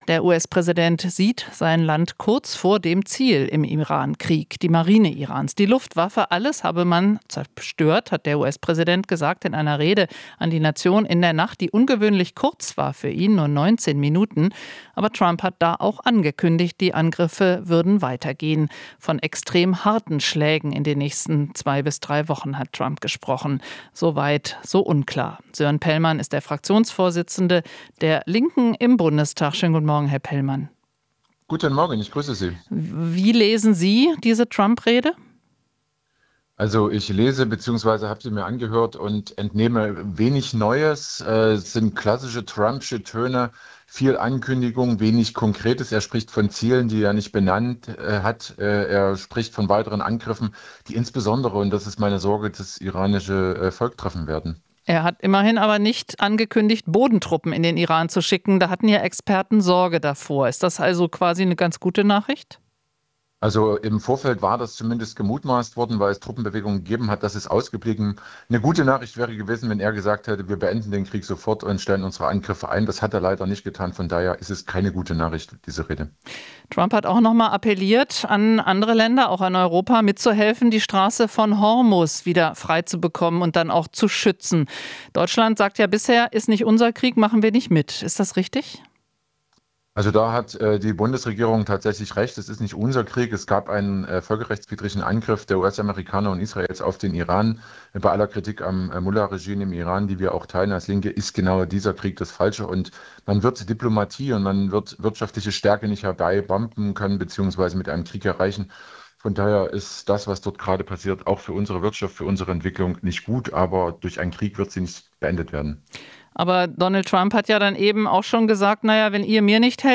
Linken-Fraktionschef Sören Pellmann sagt,